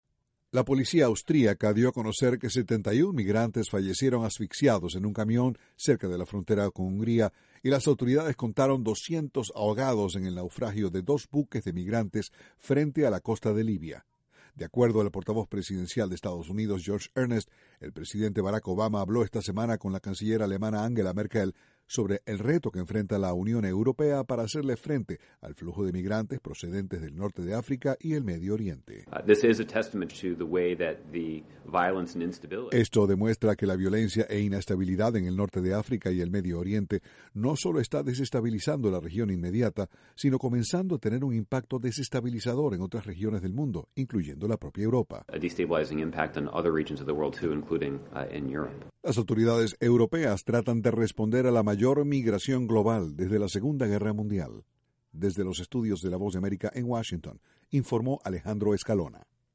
El presidente Barack Obama dijo que tiene la esperanza de que la Unión Europea dedique recursos adicionales para hacerle frente a la crisis migratoria. Desde la Voz de América, Washington, informa